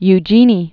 (y-jēnē, œ-zhā-nē) 1826-1920.